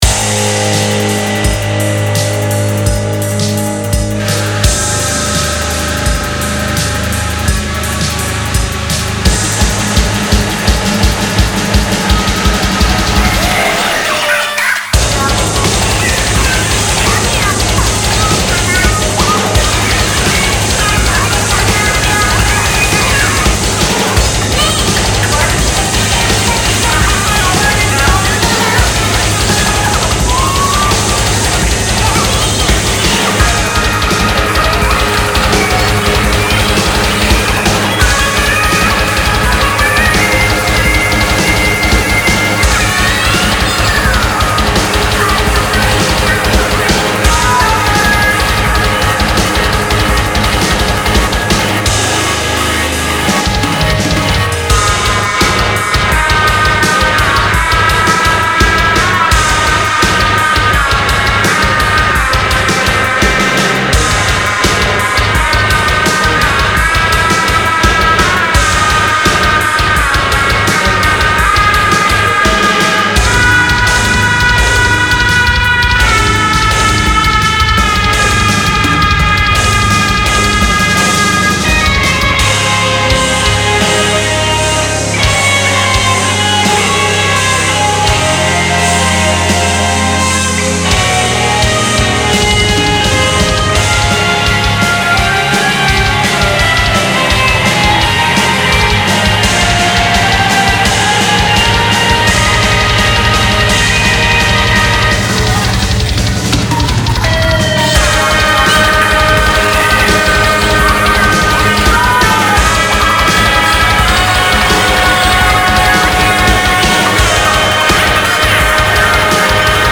BPM169
Audio QualityPerfect (High Quality)
-cwapping
-yodewing denpa singew
-euwobeat...down't awsk why, idk